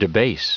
Prononciation du mot debase en anglais (fichier audio)
Prononciation du mot : debase